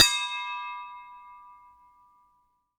bell_small_ringing_02.wav